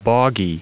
Prononciation du mot boggy en anglais (fichier audio)
Prononciation du mot : boggy